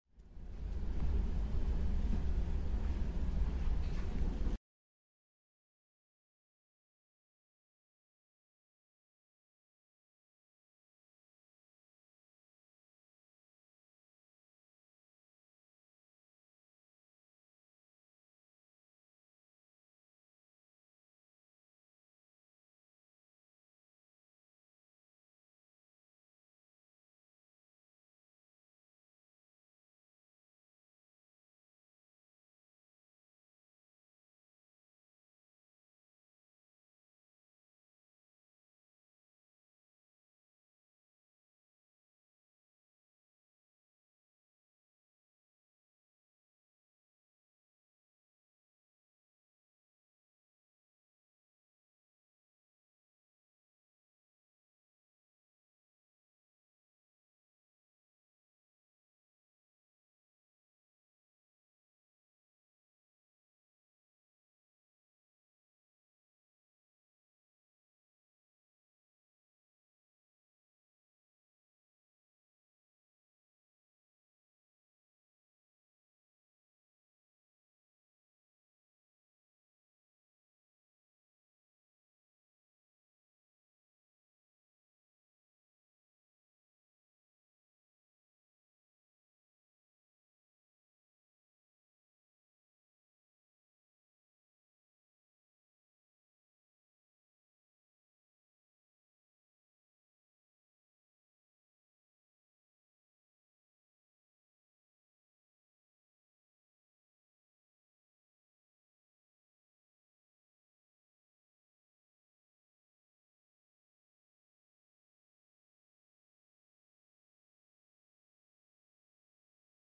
Real-Time Café Ambience – 1 Hour of Coffee Shop Focus